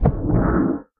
Minecraft Version Minecraft Version 25w18a Latest Release | Latest Snapshot 25w18a / assets / minecraft / sounds / mob / guardian / elder_hit1.ogg Compare With Compare With Latest Release | Latest Snapshot
elder_hit1.ogg